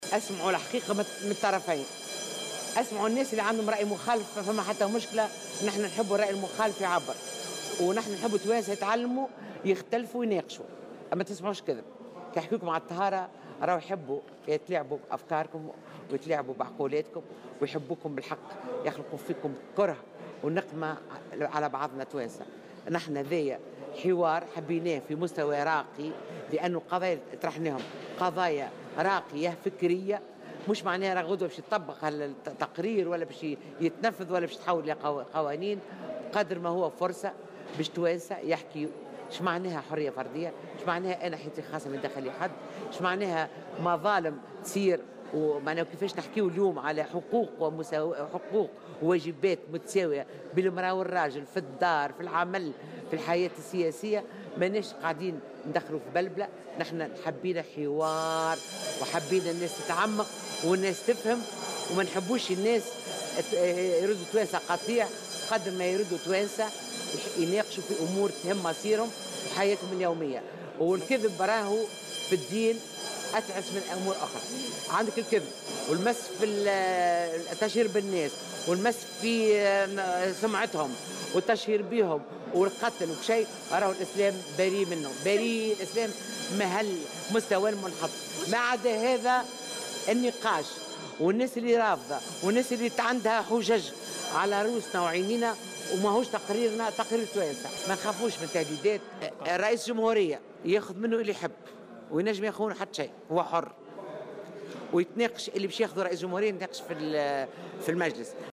وأضافت في تصريح اليوم لمراسل "الجوهرة أف ام": " عليكم الإستماع إلى الحقيقة و إلى الرأي المخالف لكن ما "تسمعوش الكذب".وأوضحت أن التقرير فرصة حقيقية للحوار والنقاش حول قضايا فكرية و مسائل تهم الحياة اليومية، وفق قولها.